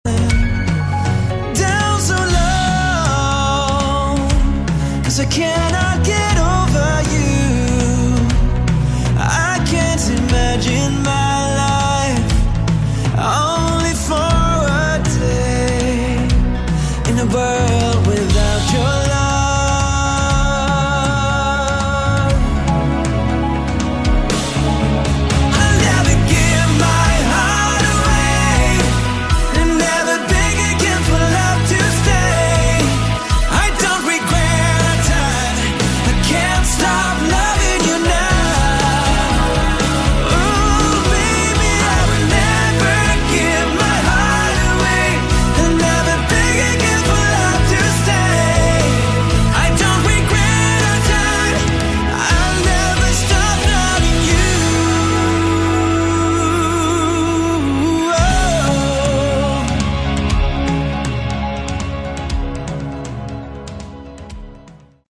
Category: AOR
guitar, vocals, keyboards
bass
drums